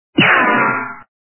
При прослушивании Звук - Рикошет качество понижено и присутствуют гудки.
Звук Звук - Рикошет